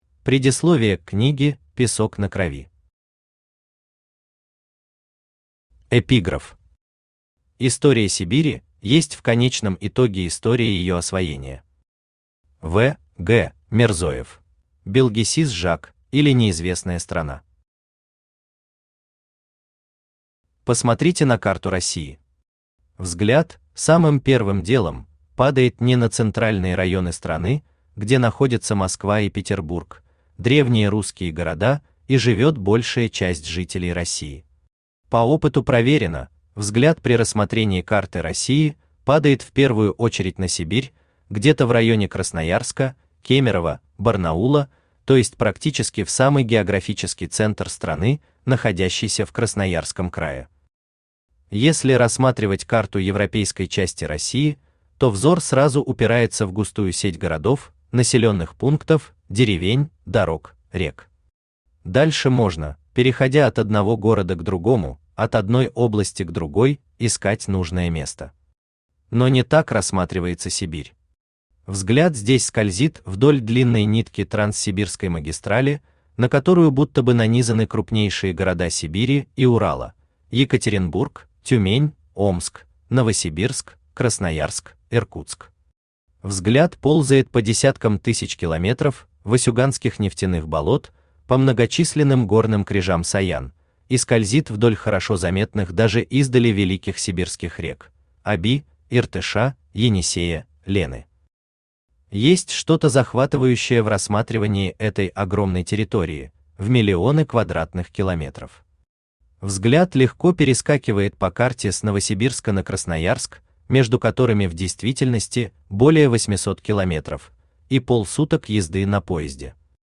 Аудиокнига Покорение Сибири: мифы и реальность | Библиотека аудиокниг
Aудиокнига Покорение Сибири: мифы и реальность Автор Дмитрий Николаевич Верхотуров Читает аудиокнигу Авточтец ЛитРес.